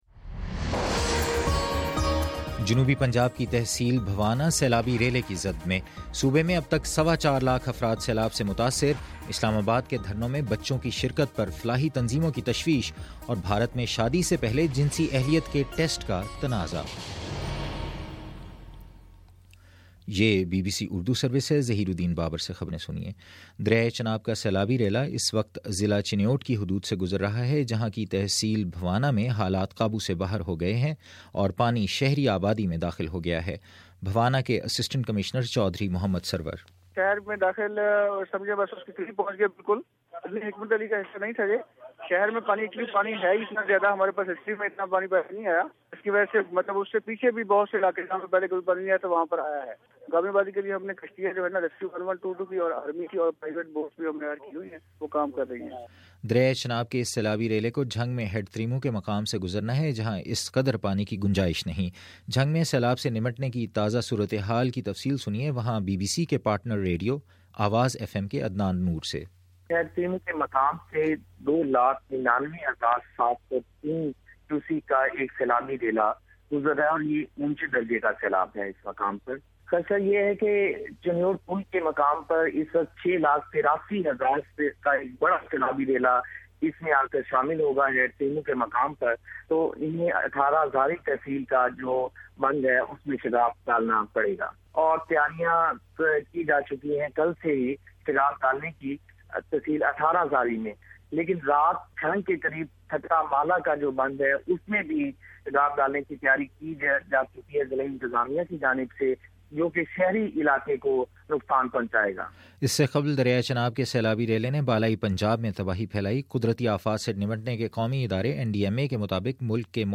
دس منٹ کا نیوز بُلیٹن روزانہ پاکستانی وقت کے مطابق صبح 9 بجے، شام 6 بجے اور پھر 7 بجے۔